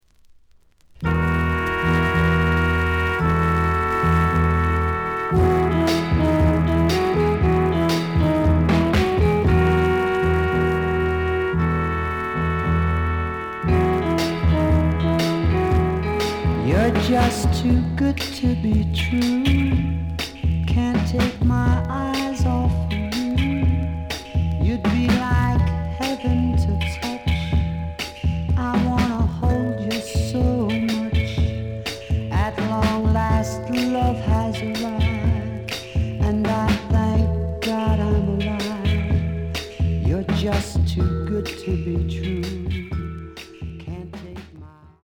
The audio sample is recorded from the actual item.
●Genre: Rock / Pop
Slight noise on A side.)